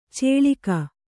♪ cēḷika